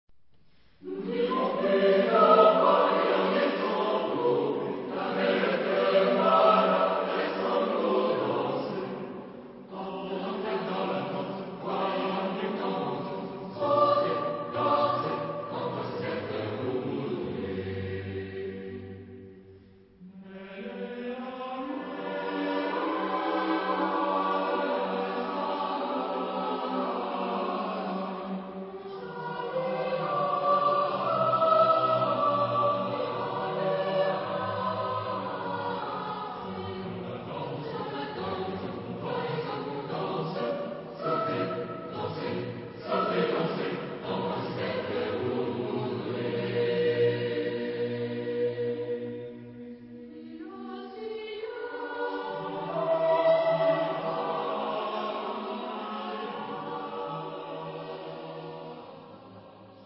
Genre-Style-Forme : Populaire
Type de choeur : SATB  (4 voix mixtes )
interprété par Maîtrise de garçons de Colmar